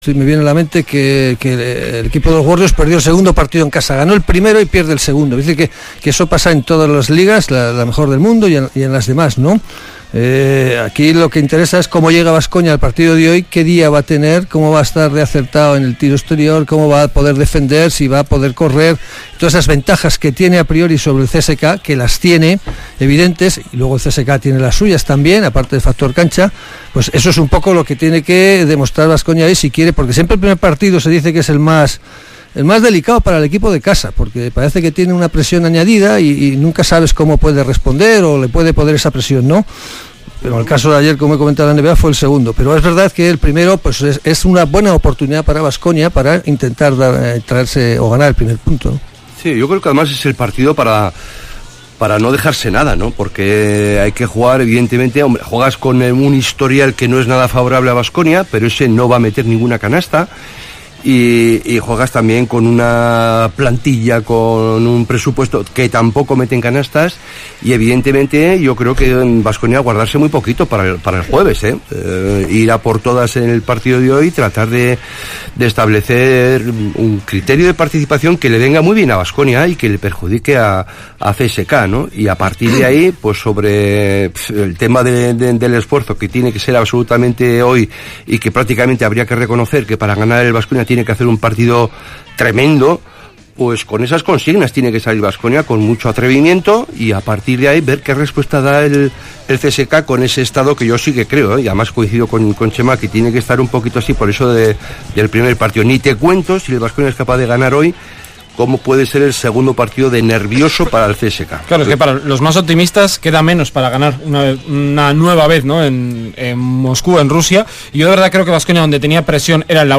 CSKA Moscu-Kirolbet Baskonia 1er partido cuartos final euroleague 2018-19 retransmisión Radio Vitoria